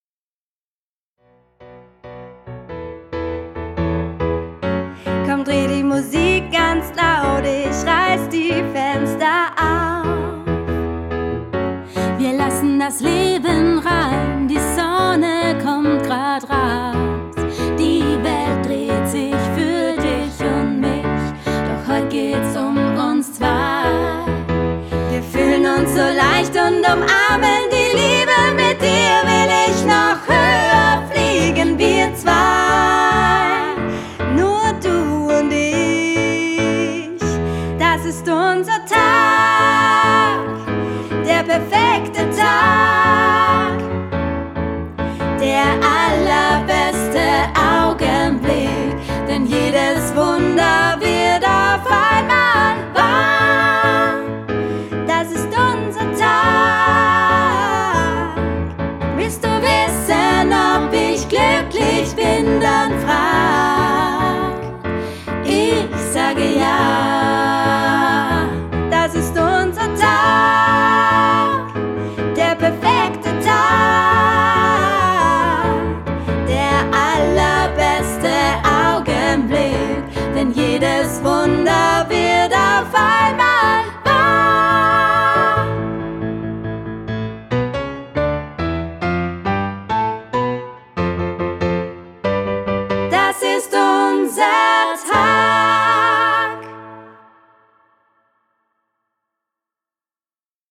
Quartett